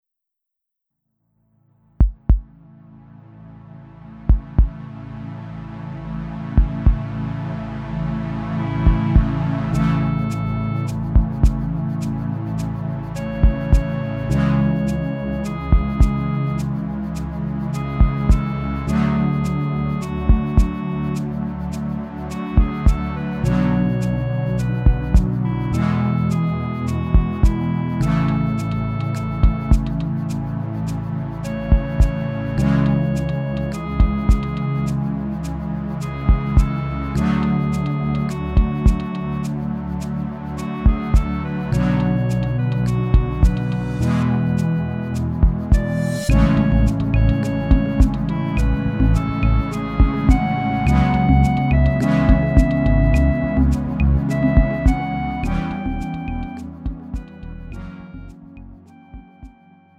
음정 -1키 3:15
장르 가요 구분 Lite MR